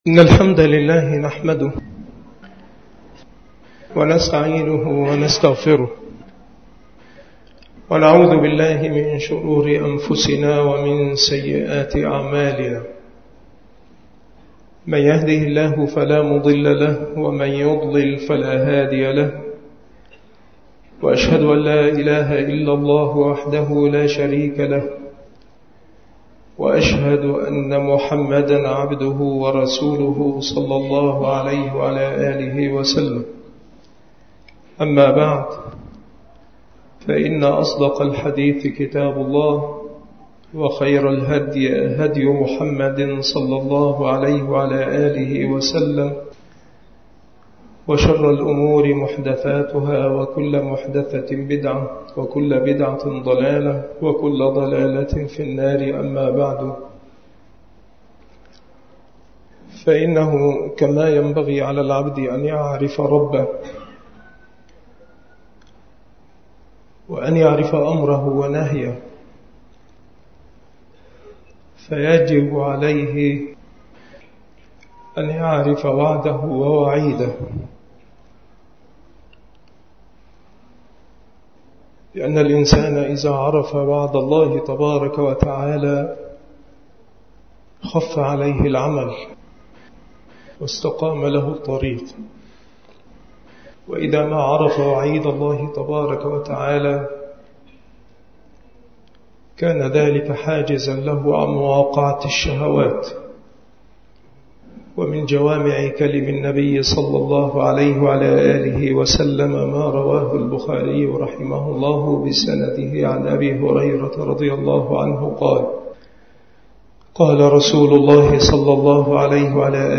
المحاضرة
مكان إلقاء هذه المحاضرة بمسجد الأنصار بأشمون - محافظة المنوفية - مصر